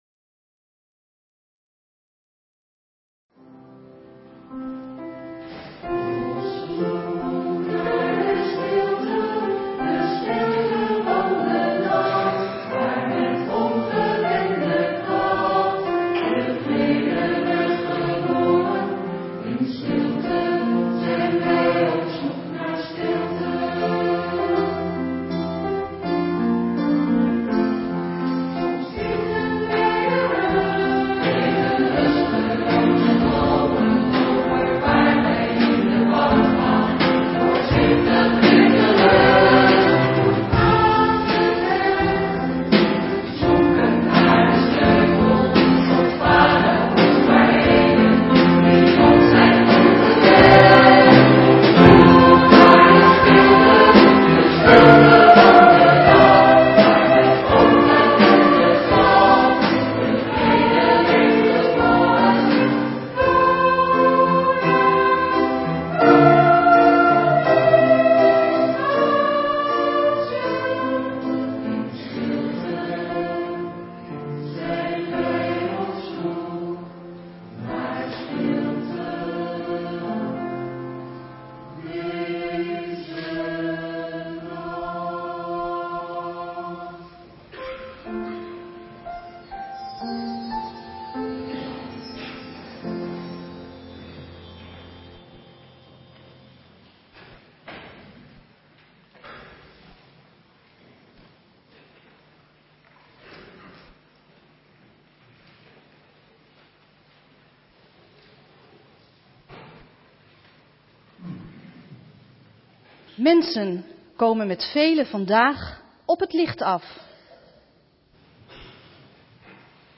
Preek Kerstavond, Hoogfeest Geboorte van Christus, 24 december 2006 | Hagenpreken
Eucharistieviering beluisteren vanuit de Jozefkerk te Wassenaar (MP3)